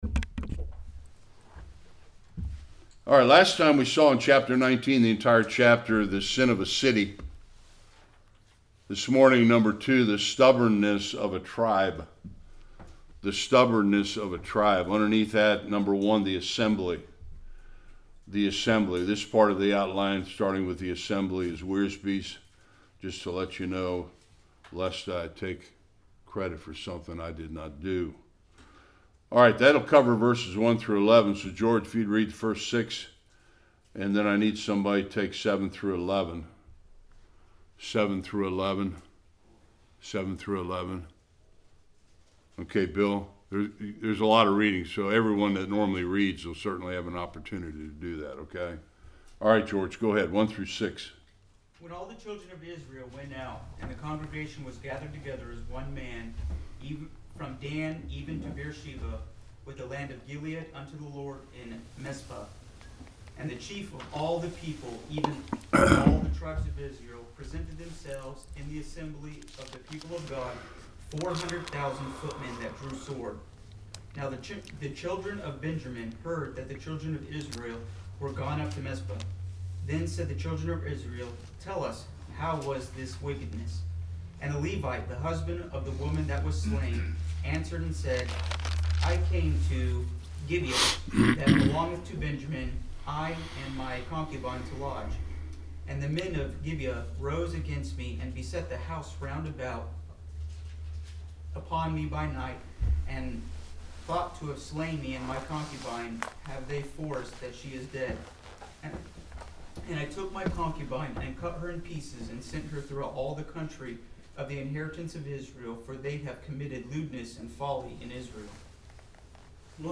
1-48 Service Type: Sunday School Body parts lead to a civil war in Israel.